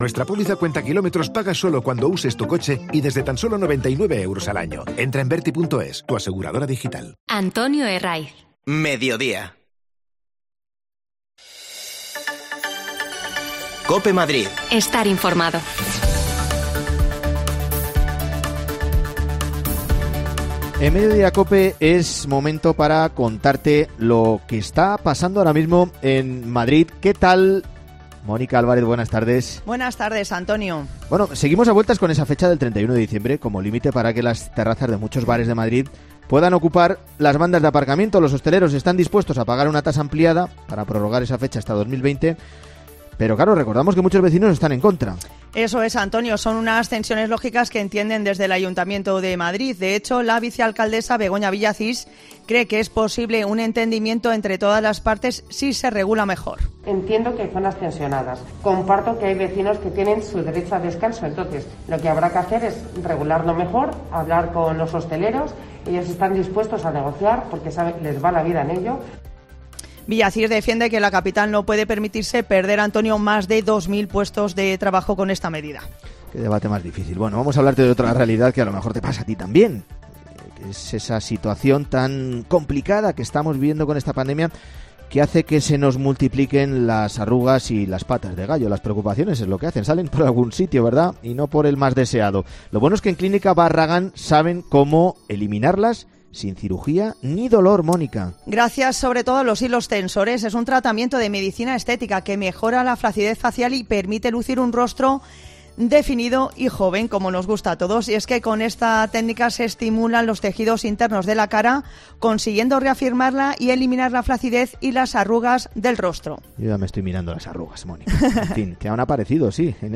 AUDIO: Charlamos con Cristina Ramos. Podemos volver a disfrutar de esta gran cantante el próximo sábado 29 de mayo en el Coliseum de Madrid
Las desconexiones locales de Madrid son espacios de 10 minutos de duración que se emiten en COPE , de lunes a viernes.